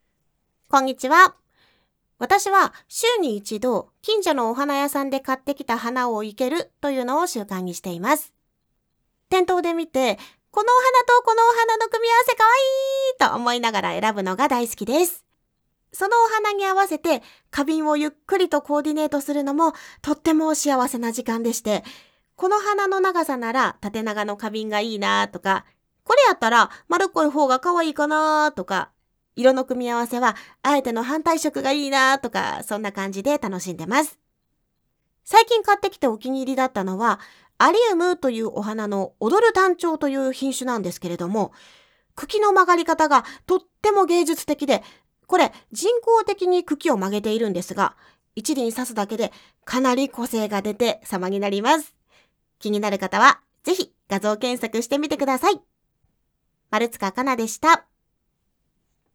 方　言　：　兵庫県
フリートーク